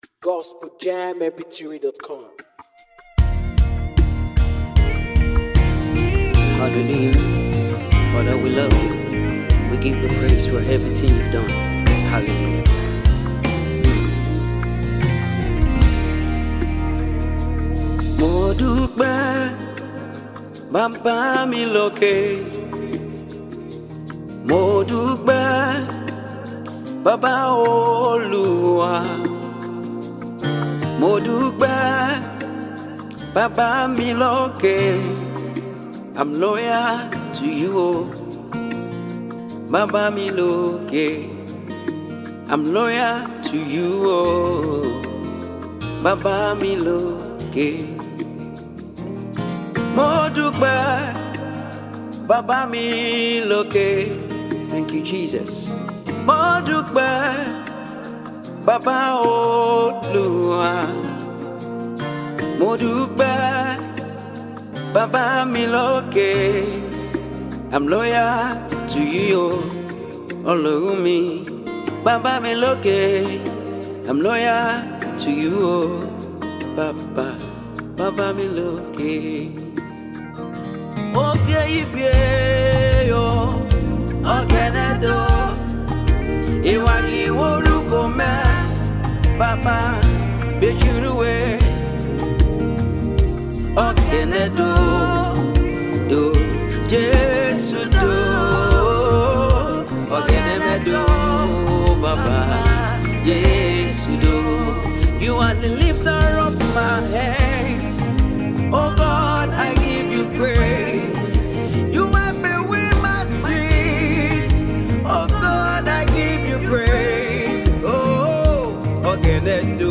powerful and spirit-lifting gospel sound
featuring the soulful voice
beautiful harmonies, and a smooth worship rhythm